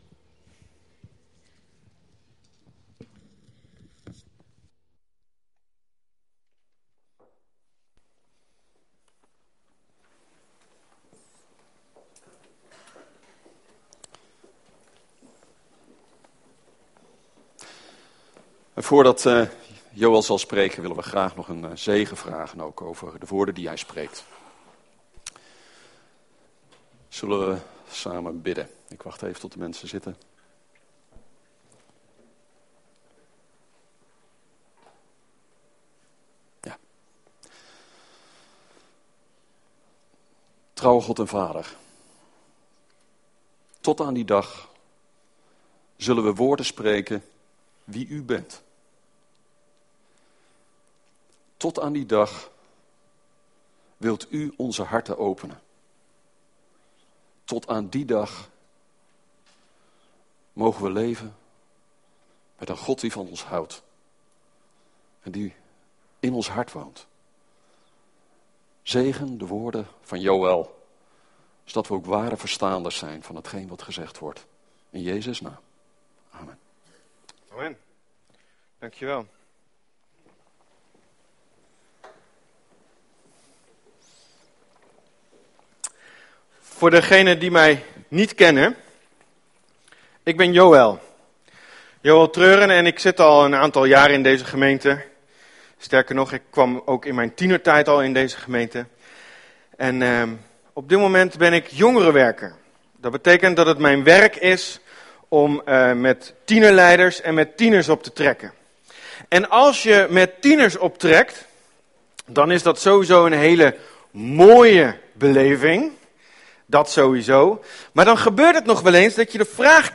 Preek
We komen elke zondagmorgen bij elkaar om God te aanbidden.